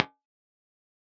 Usage note: Dry impulses are in mono, wet "Air" impulses stereo.
Mic Blend (center slider position) Dry Wet
50% Wet
RammCabMixABHalfWet.wav